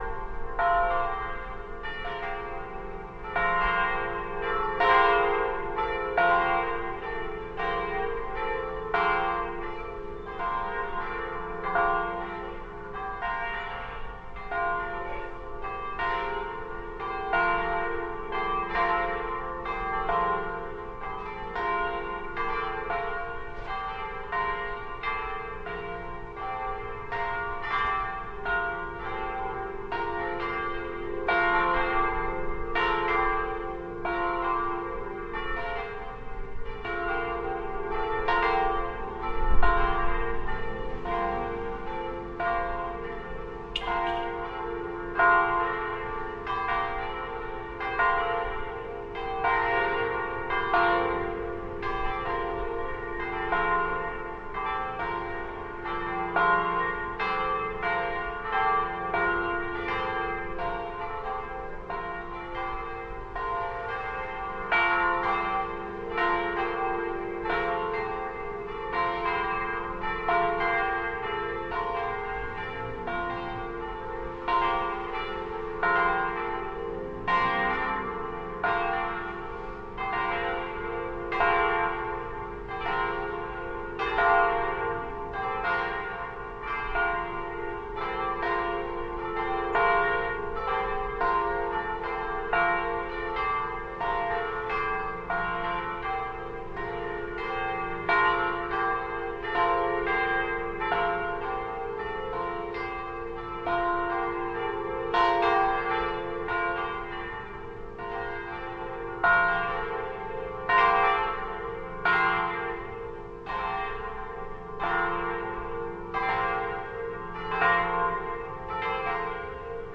Montreal » alley winter drippy +church bells Verdun, Montreal, Canada
描述：alley winter drippy +church bells Verdun, Montreal, Canada.flac
标签： bells drippy church Montreal alley winter Canada
声道立体声